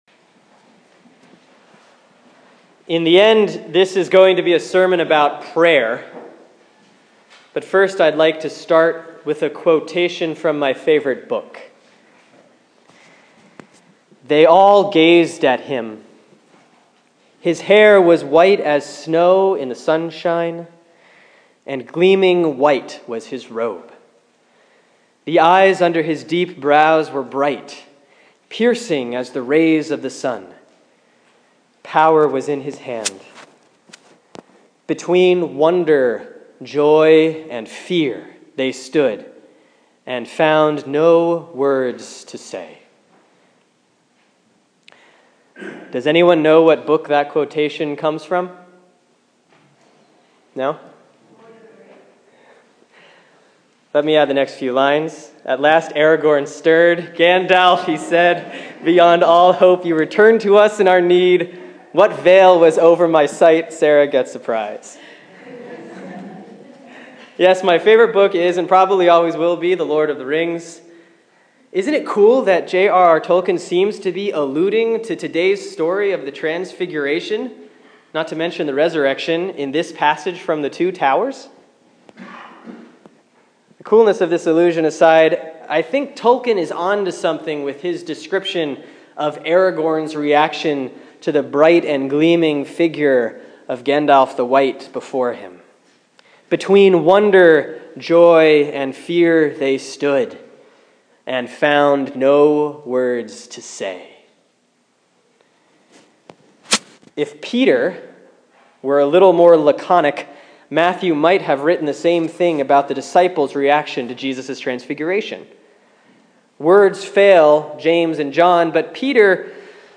Sermon for Sunday, February 7, 2016 || Last Epiphany C || Luke 9:28-36